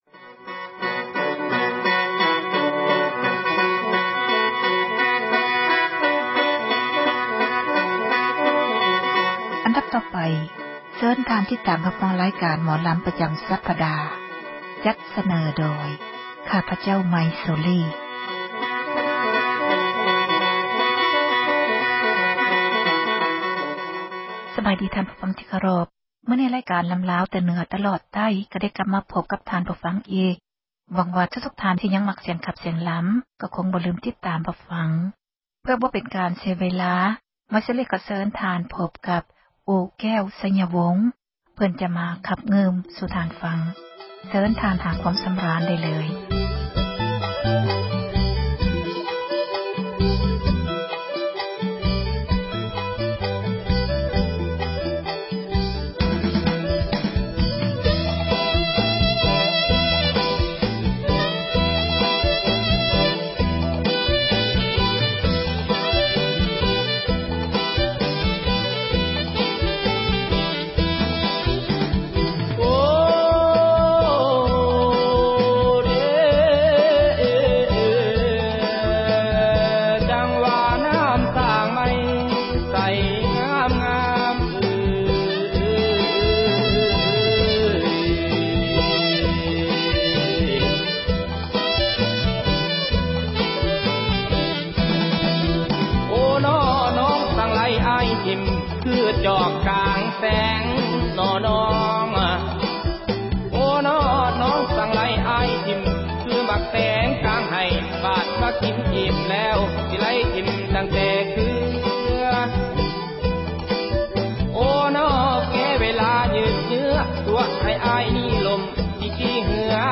ຣາຍການ ໝໍລຳລາວ ປະຈຳ ສັປດາ ຈັດສເນີ ທ່ານ ໂດຍ